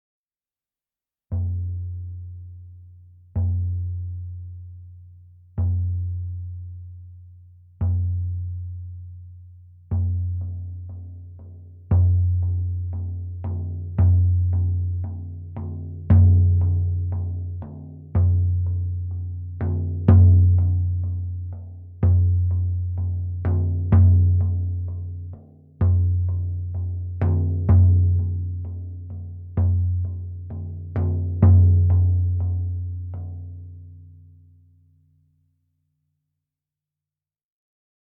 Meinl Sonic Energy Ritual Drum 20" - Ziegenfell (RD20WB)